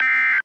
BadWeather.wav